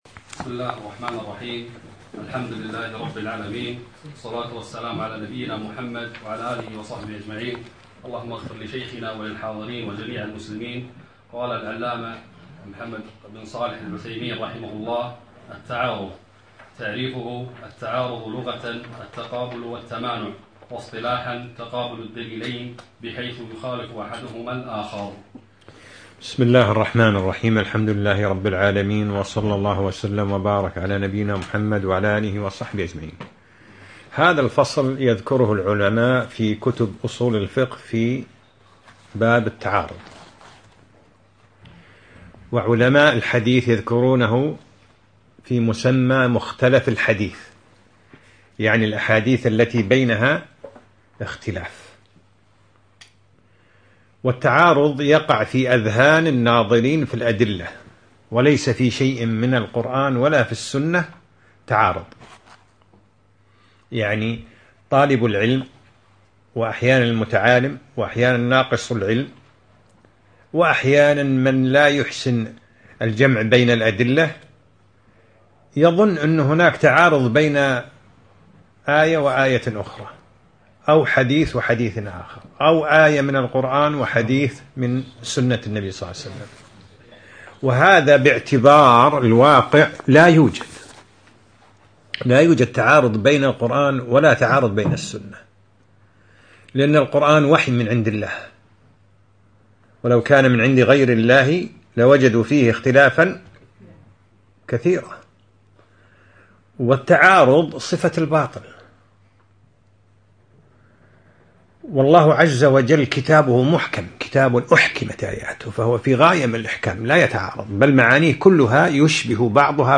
الدرس الثاني : التعارض